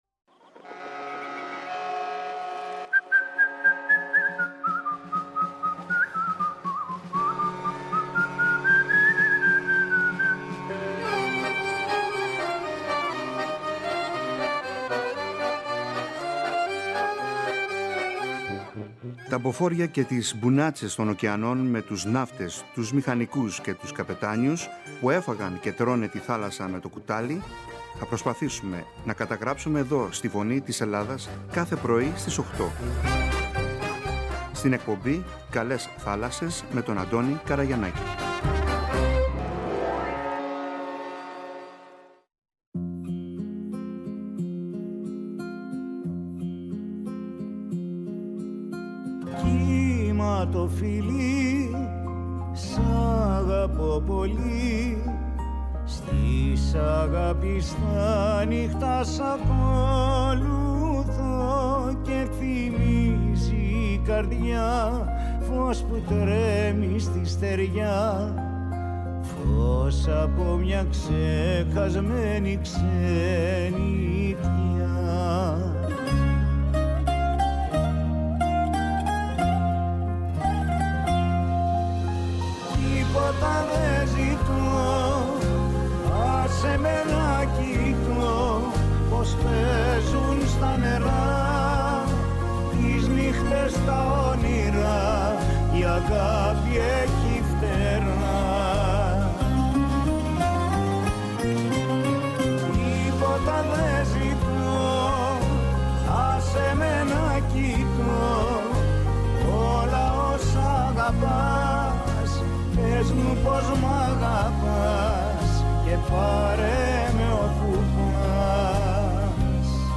Ο Γιάννης Παυλόπουλος, Αντιναύαρχος Π.Ν., Επίτιμος Αρχηγός Στόλου μίλησε στη Φωνή της Ελλάδας και στην εκπομπή «ΚΑΛΕΣ ΘΑΛΑΣΣΕΣ».